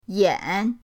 yan3.mp3